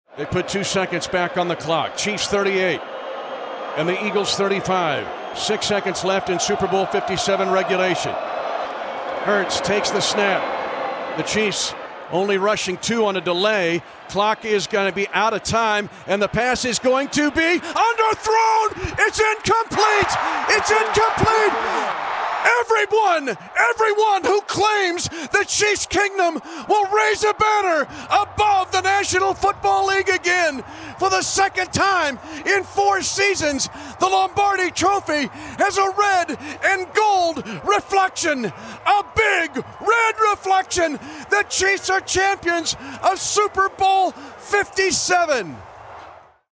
Listen: Mitch Holthus' final call of Super Bowl 57
4-21-J-Hurts-incomplete-pass-FINAL-PLAY-OF-THE-GAME-.mp3